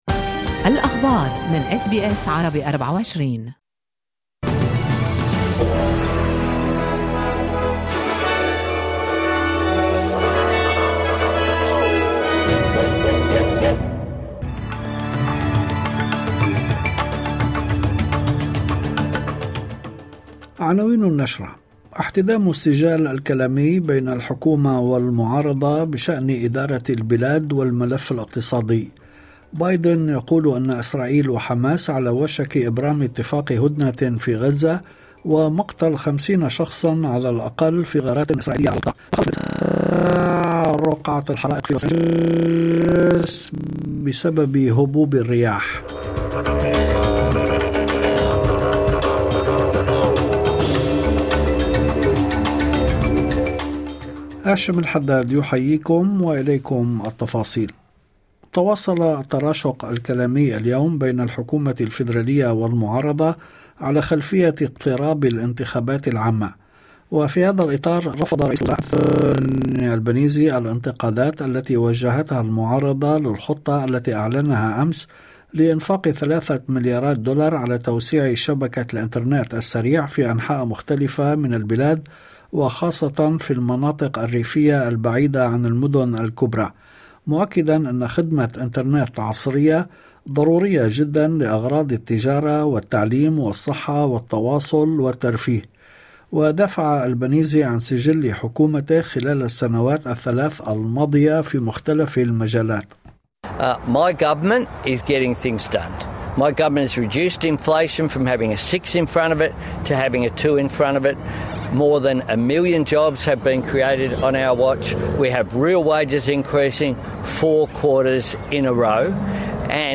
نشرة أخبار المساء 14/01/2025
نشرة الأخبار